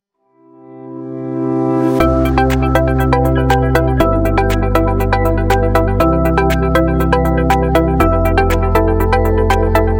🎵 Background Music
Emotion: hope